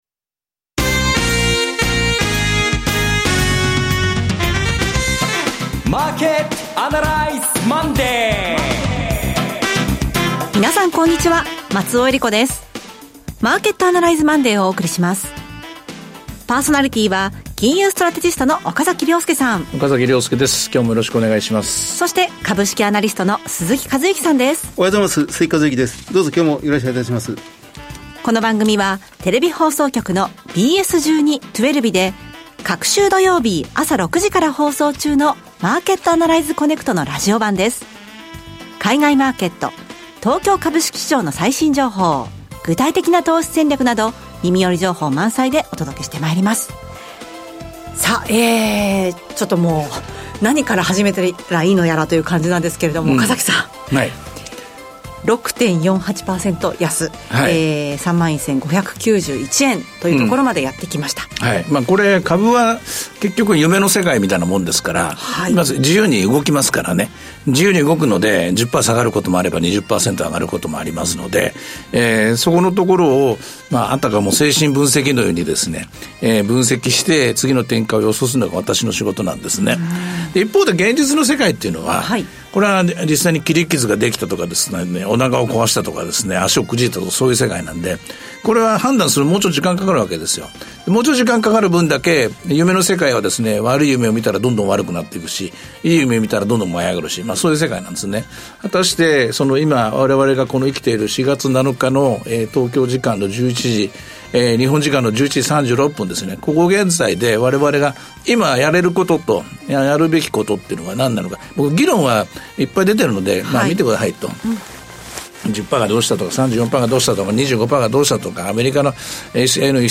ＢＳ１２ ＴwellＶの「マーケット・アナライズ コネクト」のラジオ版。今週のマーケットはどうなるか？投資家はどう対応すればよいのか、等を２５分間に凝縮してお届けします。